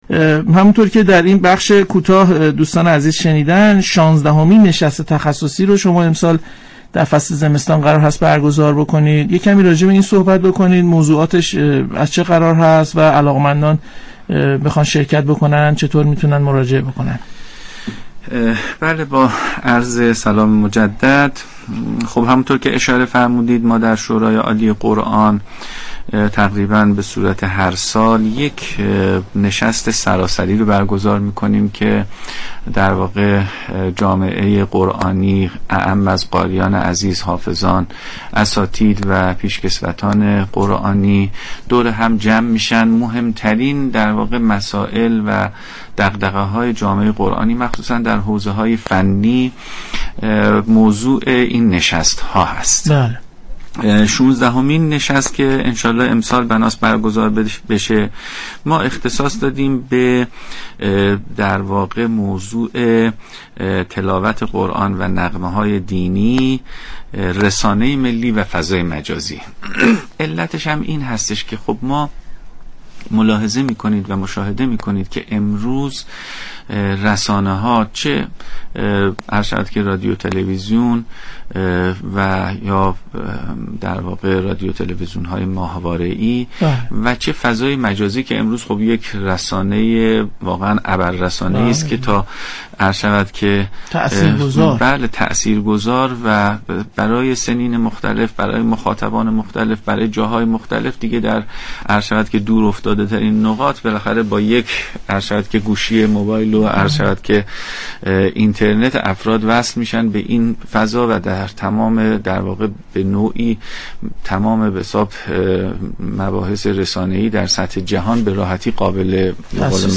سید علی سرابی قائم مقام شورای عالی قرآن در گفتگو با برنامه افق 22 آبان 1400 گفت: طرح های ارسالی باید در عین برخورداری از استانداردهای لازم، از جذابیت‌های عمومی هم برخوردار باشد.